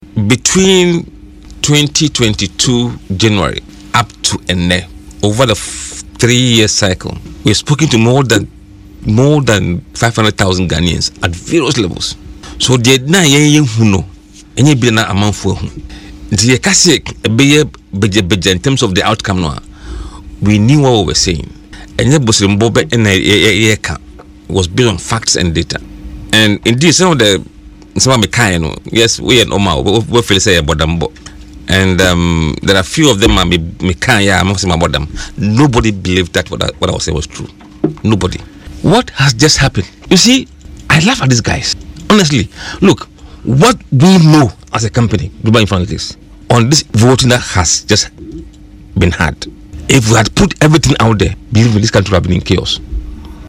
during an interview on Adom FM’s Burning Issues.